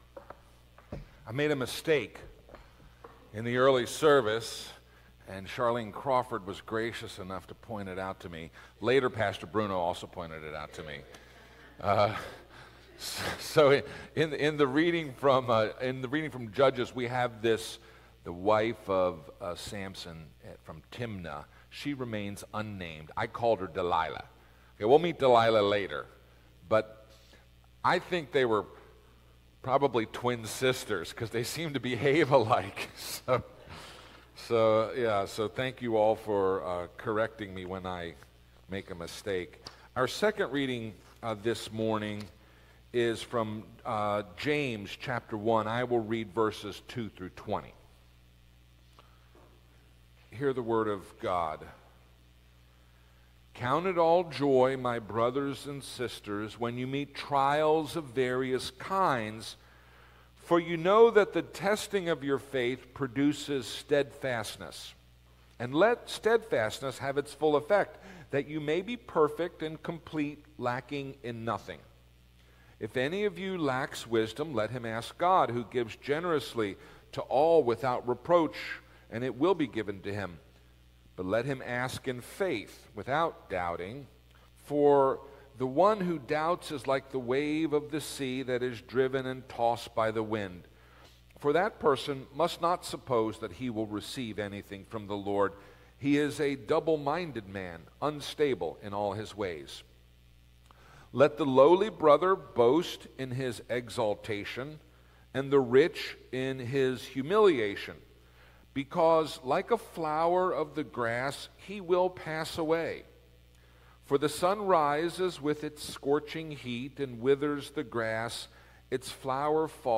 Sermons from Huntingdon Valley Presbyterian Church